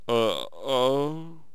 shaman_die2.wav